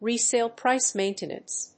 アクセントresále prìce màintenance